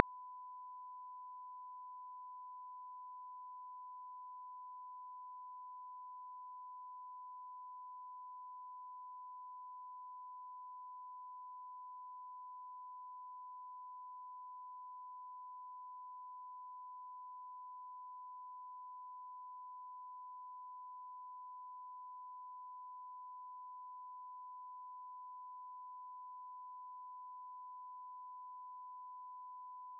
Apriamo Audacity e creiamo un nuovo suono da Generate -> Tone, impostiamo una frequenza di 1000 Hz per poterlo udire e lasciamo inalterati gli altri parametri.
Infine, spostate il bilanciamento di una traccia tutto a sinistra (L) e quello dell’altra tutto a destra (R).
In questo esempio il rumore eliminato è un semplice suono a frequenza costante, ma ci consente di capire il principio su cui si basano sistemi molto più complessi di eliminazione del rumore, che invece di basarsi su suoni preregistrati analizzano il rumore identificandolo in base a determinati parametri e generano un suono uguale dall’ampiezza opposta, che annulla il rumore.